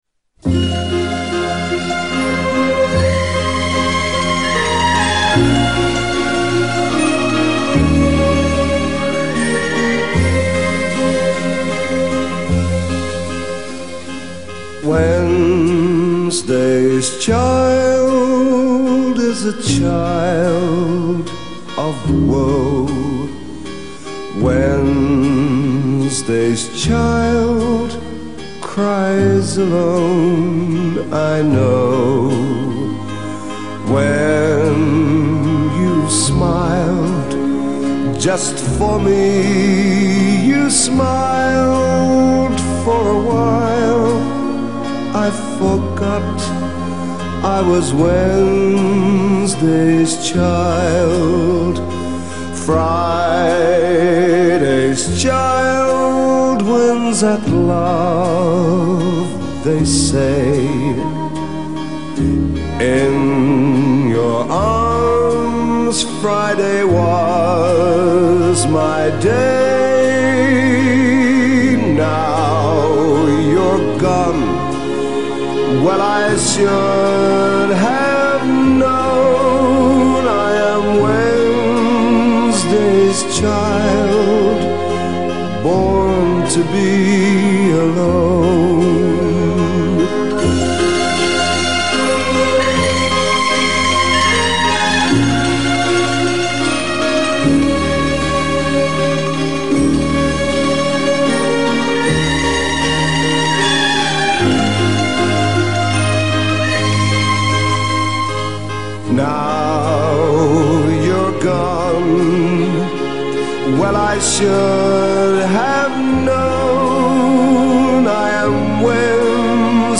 Для тех, кому может быть интересно - вокальный вариант.